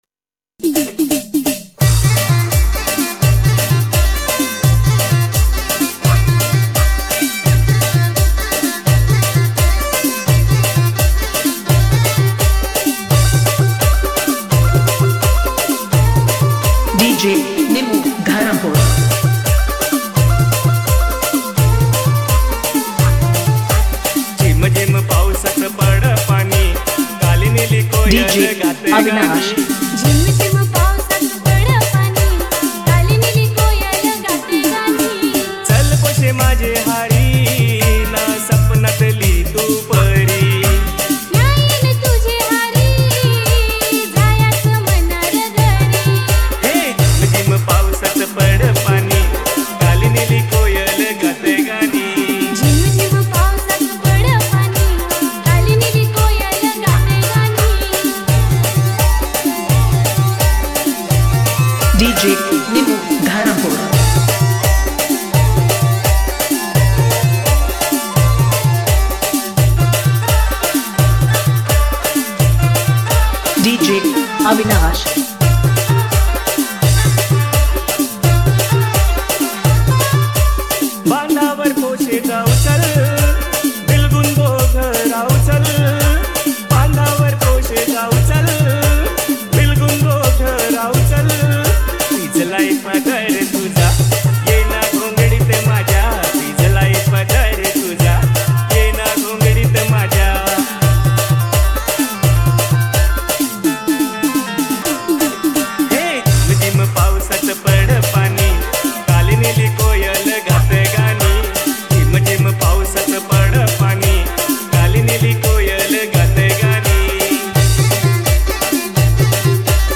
Dj Remix Gujarati